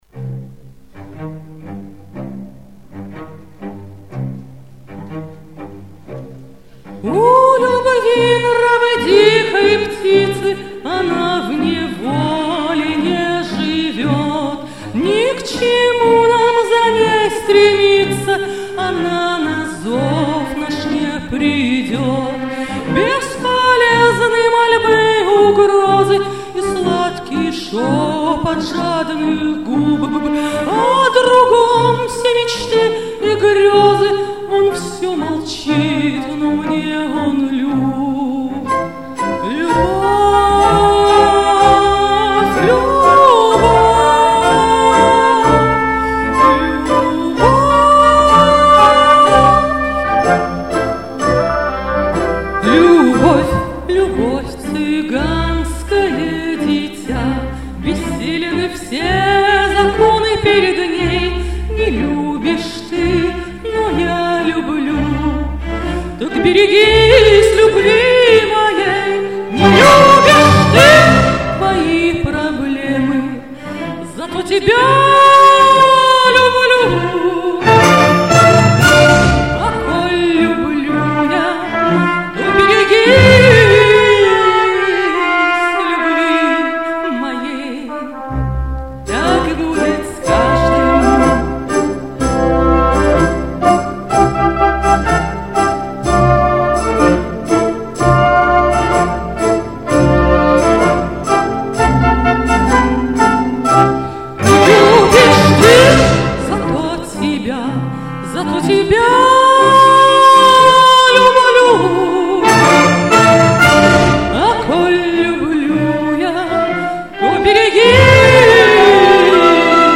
У обеих участниц красиво звучит голос, артистичное исполнение, интересный текст.